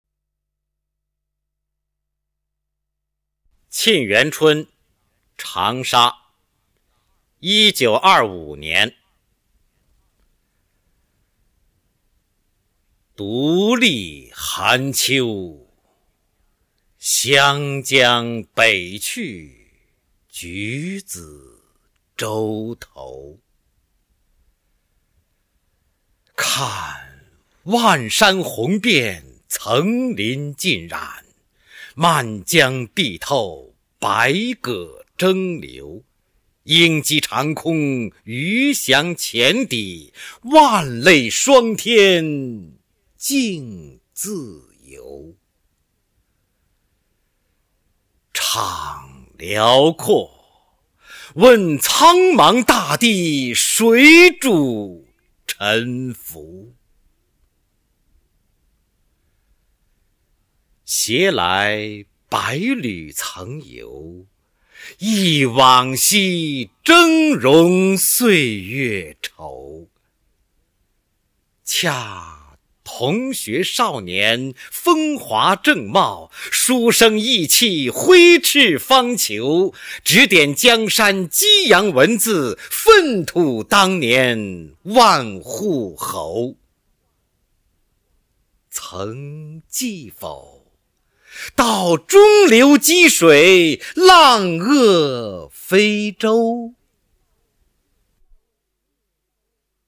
毛泽东-沁园春·长沙（男） 配乐朗诵